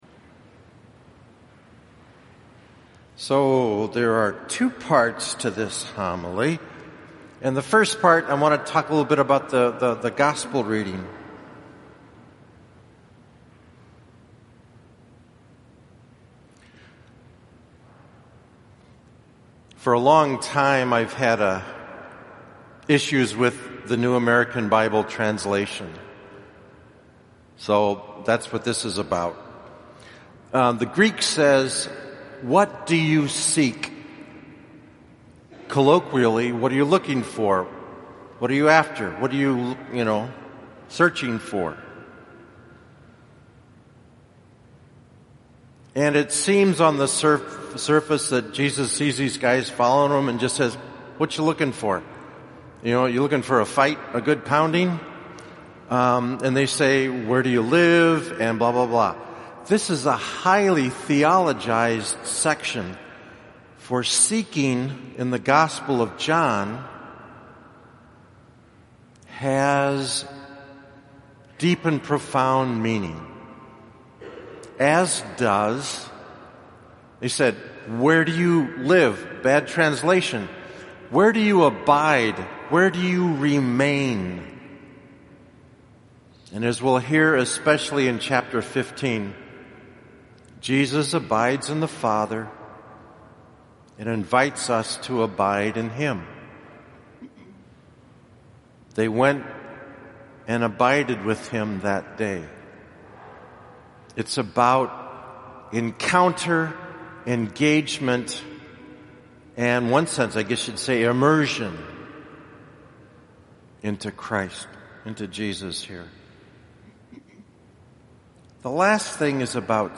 2015 Homilies – Cycle B
2015 Homilies at Gesu – Cycle B